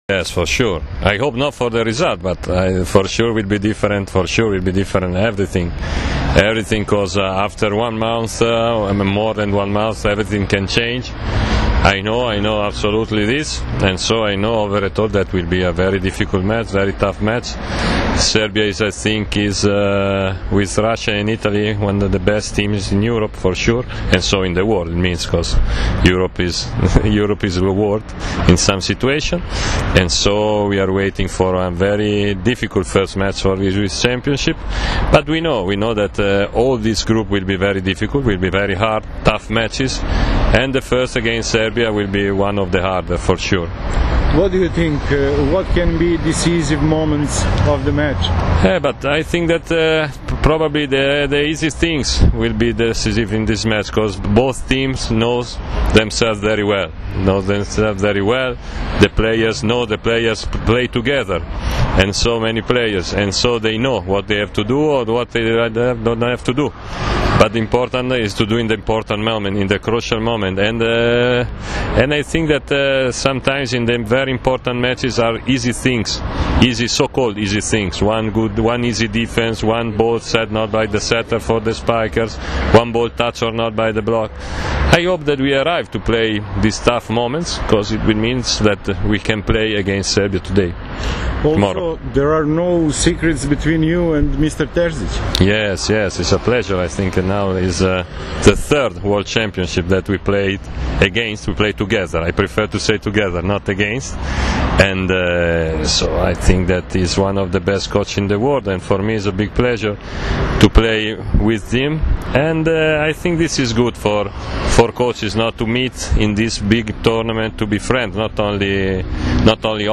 IZJAVA MASIMA BARBOLINIJA